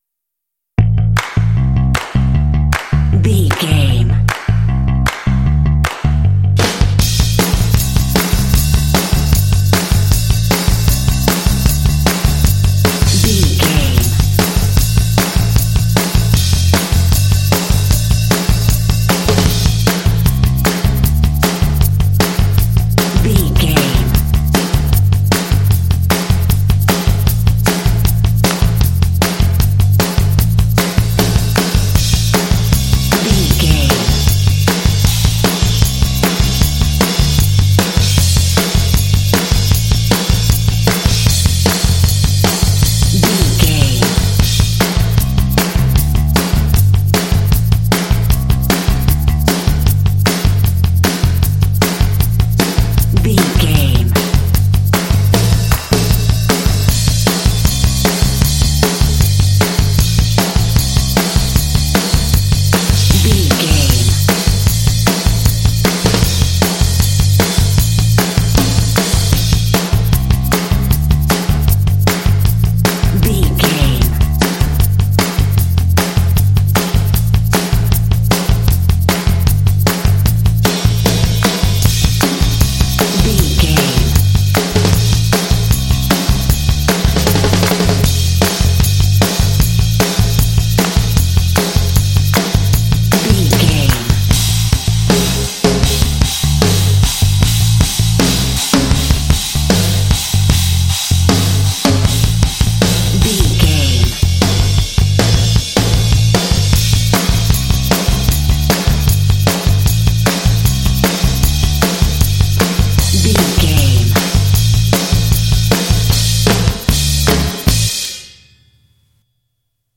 This Pop track is full of energy and drive.
Ionian/Major
driving
energetic
electric guitar
bass guitar
drums
electric piano
vocals
pop